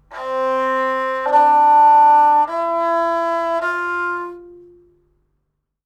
Sounds on strings in Qeychak are like this: